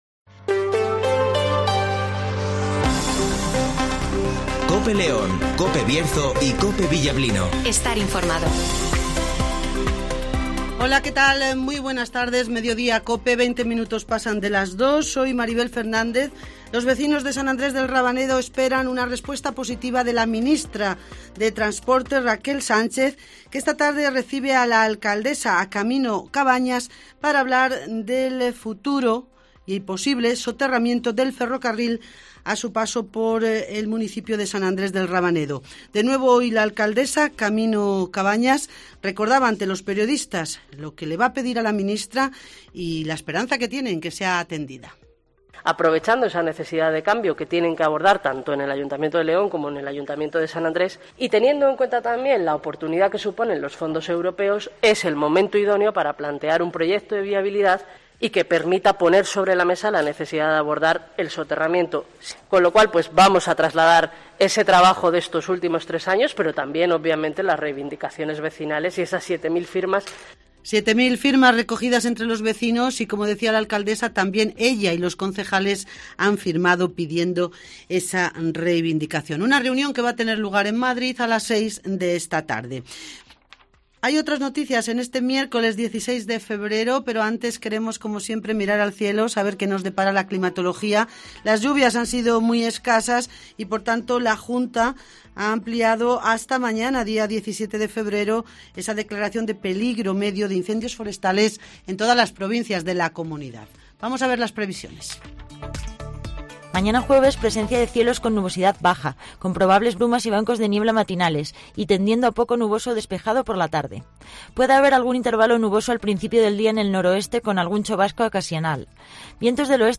- Camino Cabañas ( Alcaldesa de " San Andrés del Rabanedo " )
- José Antonio Díez ( Alcalde de León )